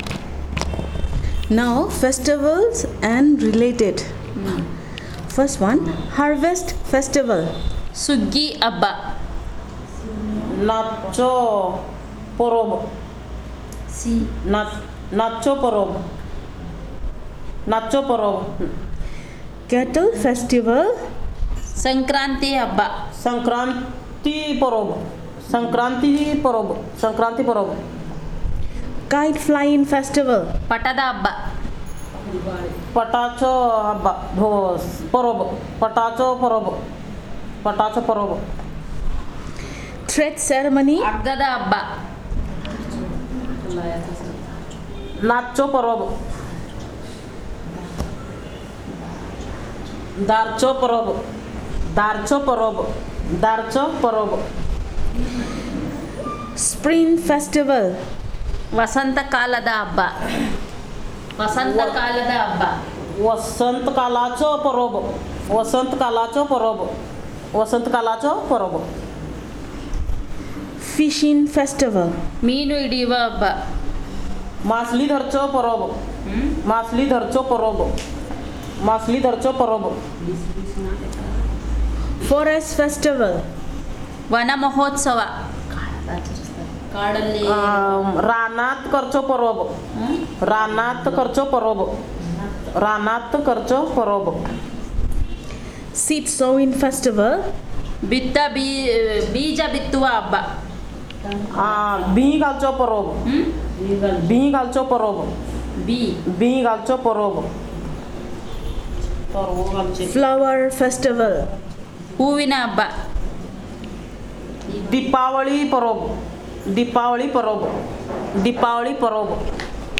Elicitation of words about festival and related